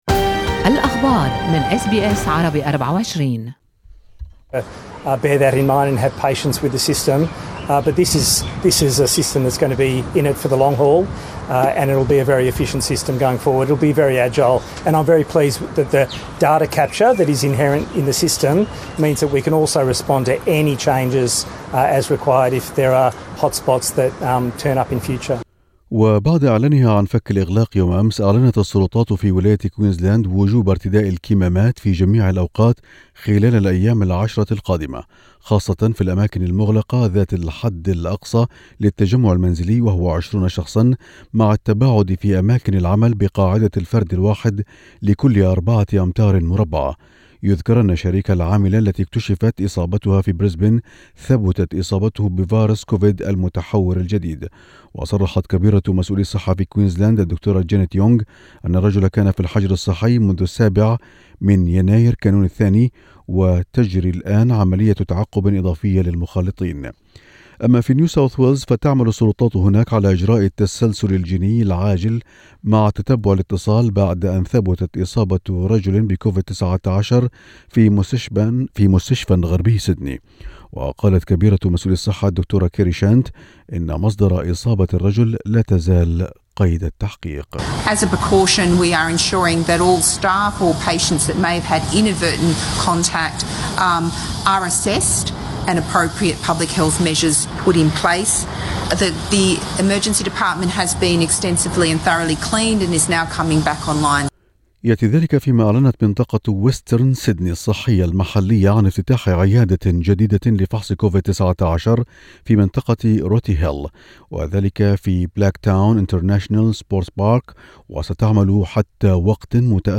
نشرة أخبار الصباح 12/1/2021